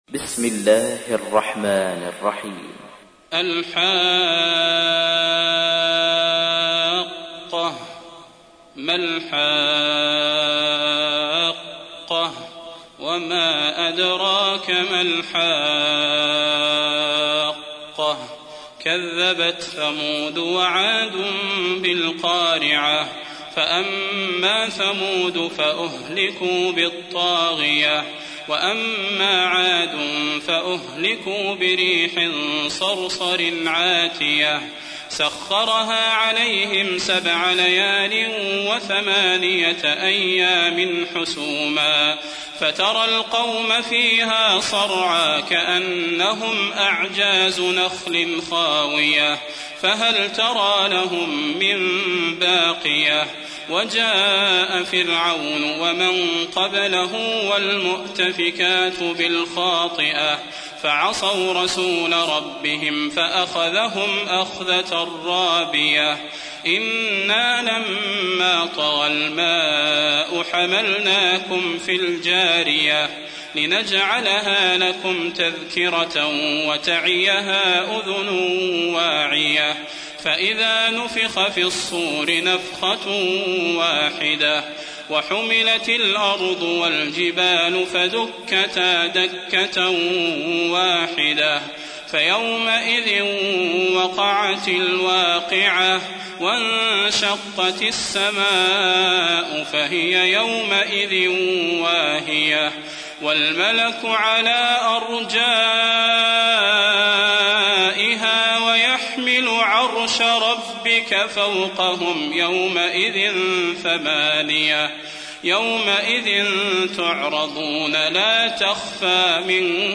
تحميل : 69. سورة الحاقة / القارئ صلاح البدير / القرآن الكريم / موقع يا حسين